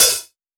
• Mellow Urban Open Hi Hat One Shot D# Key 04.wav
Royality free open hi hat sound tuned to the D# note. Loudest frequency: 7114Hz
mellow-urban-open-hi-hat-one-shot-d-sharp-key-04-v27.wav